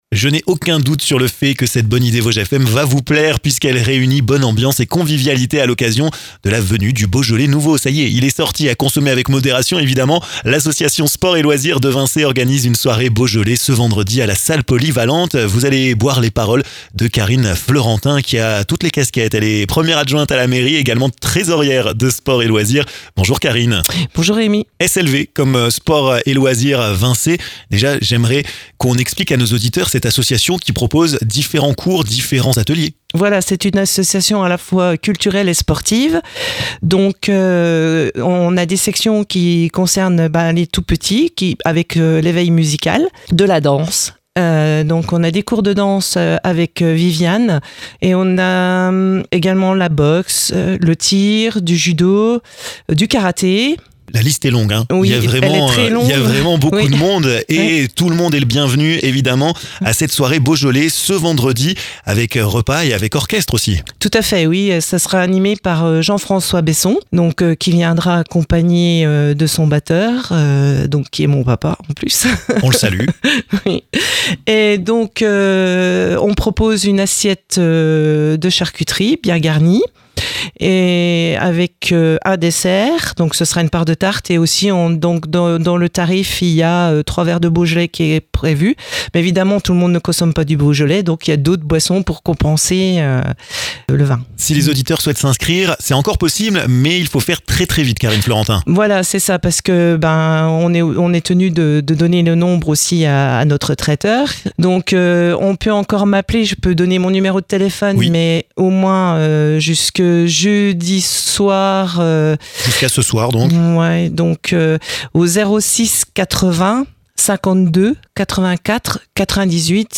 Rendez-vous à Vincey pour le tester lors d'une soirée avec orchestre! Karine Fleurentin, 1ère adjointe à la mairie et trésorière de l'association sport et loisirs de Vincey, vous en dit plus dans ce podcast!